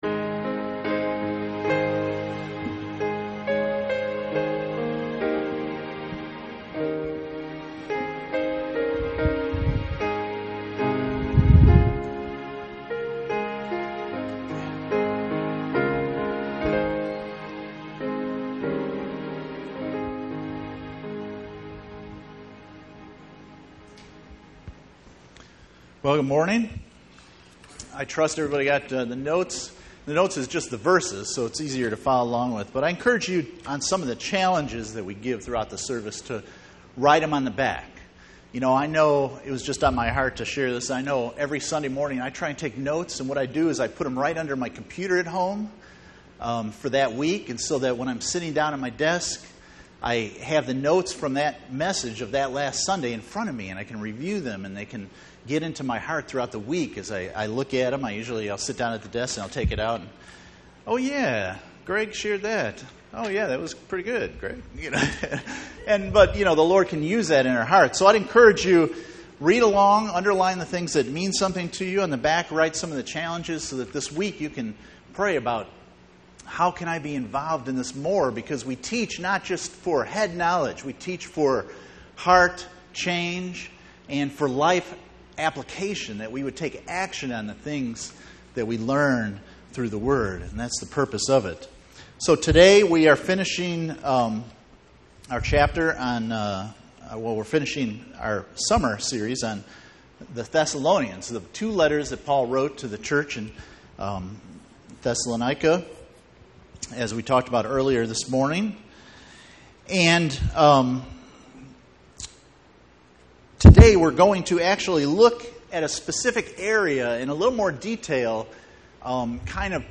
Passage: 2 Thessalonians 3:1-18 Service Type: Sunday Morning